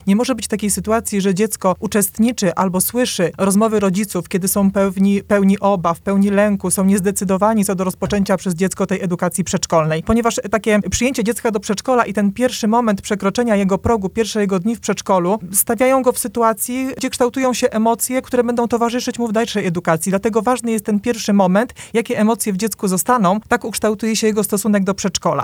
Na antenie Twojego Radia rozmawialiśmy o tym, jak przygotować siebie oraz dzieci do pierwszych dni w przedszkolu czy żłobku.